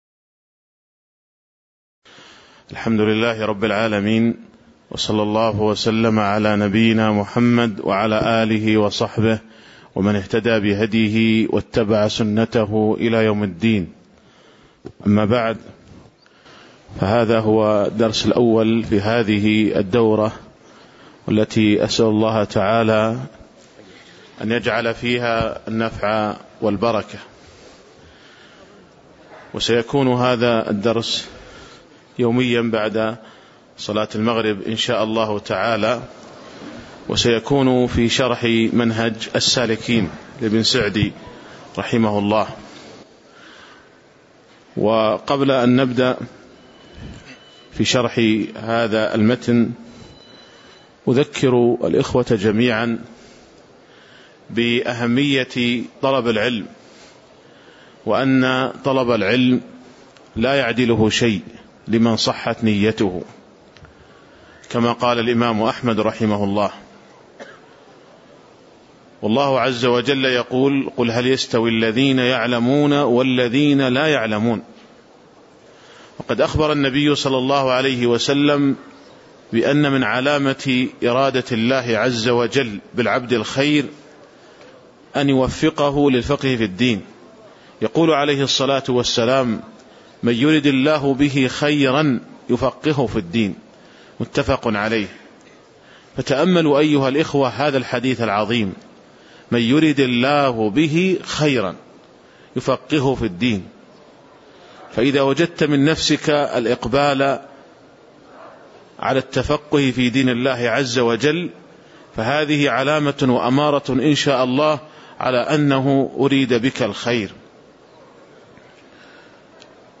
تاريخ النشر ١٢ صفر ١٤٣٨ هـ المكان: المسجد النبوي الشيخ